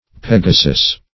Pegasus \Peg"a*sus\, n. [L., fr. Gr. Ph`gasos.]